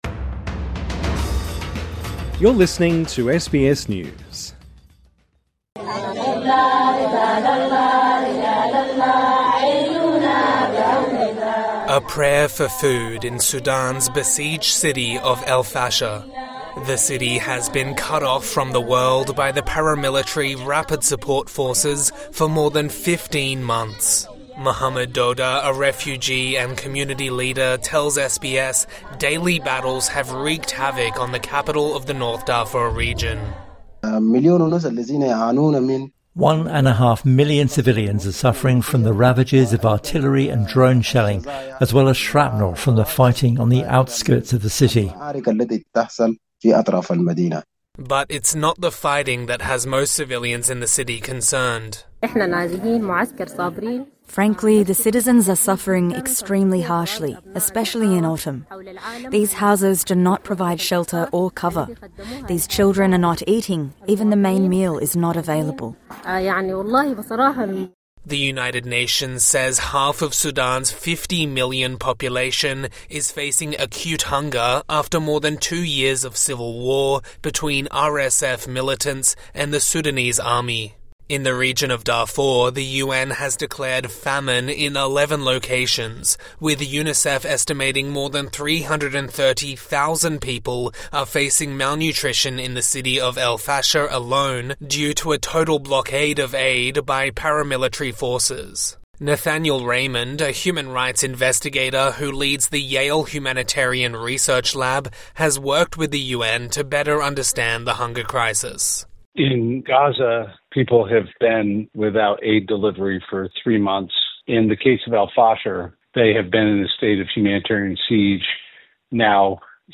TRANSCRIPT Women praying -singing in Arabic A prayer for food in Sudan's besieged city of Al-Fashir.